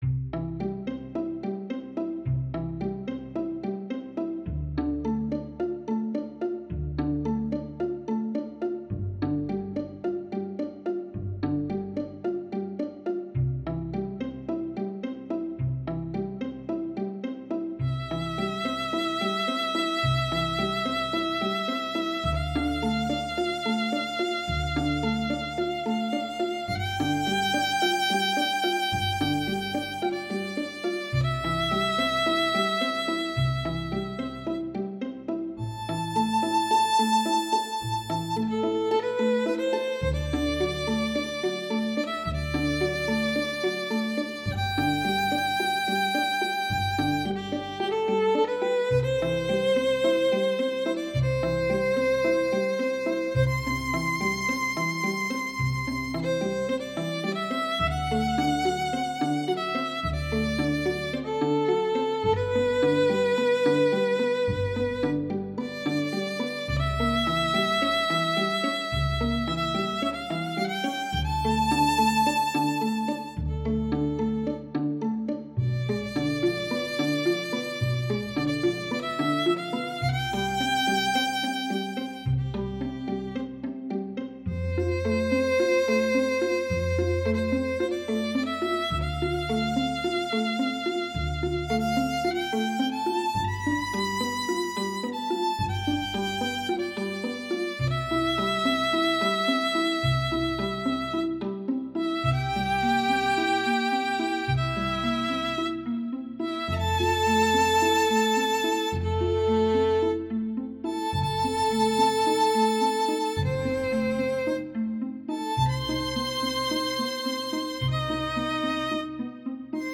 ● Violino I
● Violino II
● Viola
● Violoncelo
● Contrabaixo